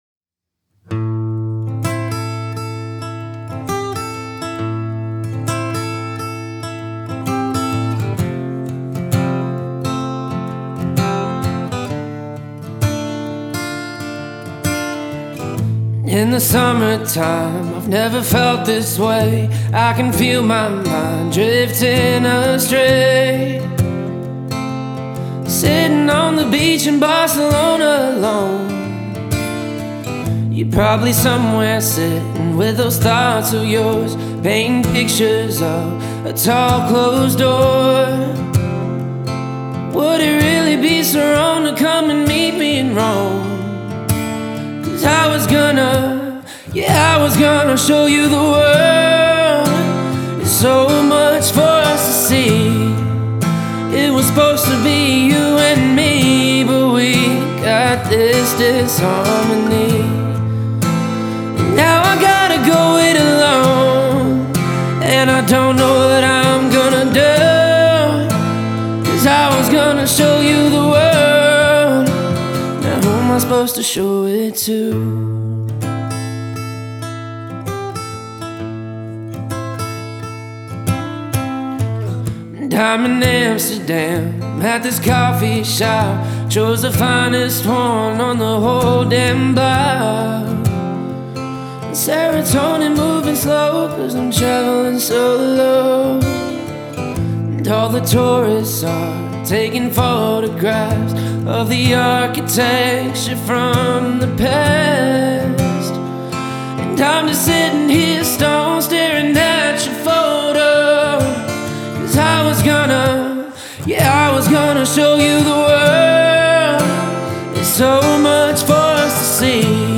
проникновенная акустическая композиция
выполненная в жанре кантри.